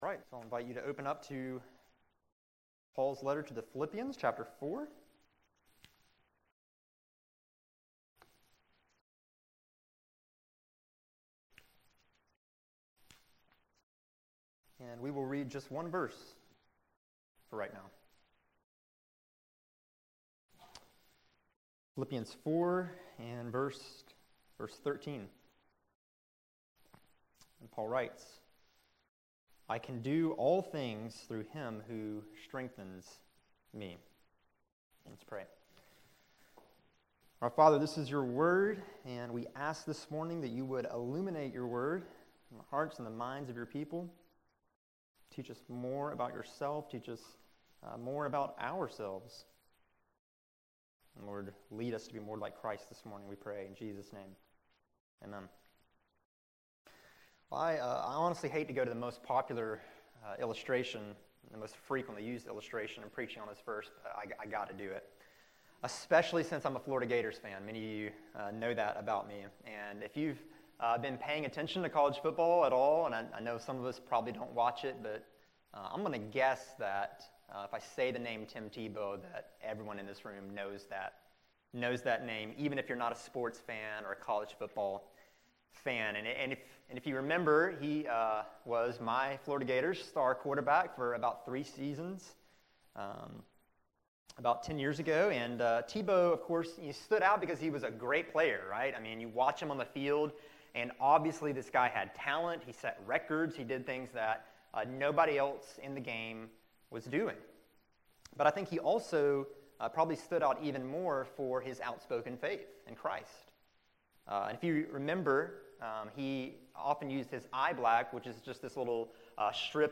July 24, 2016 Morning Worship | Vine Street Baptist Church